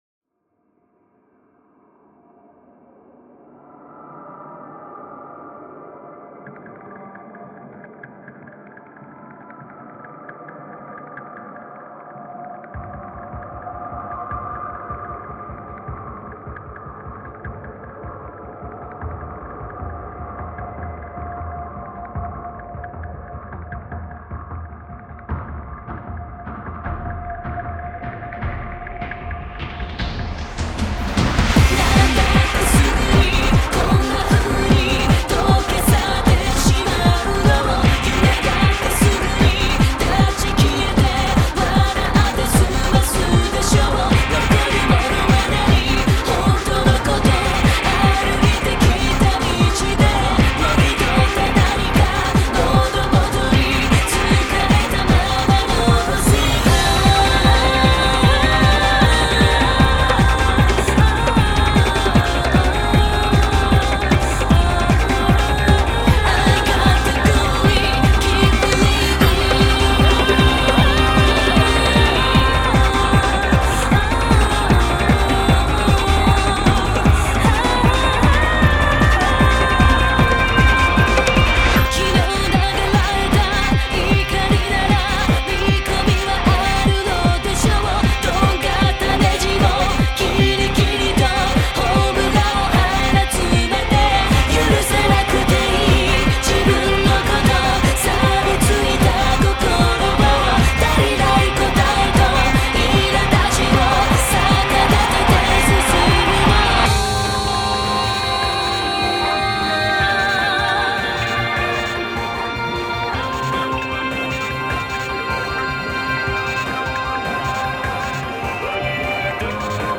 Genre: J-Pop, Female Vocal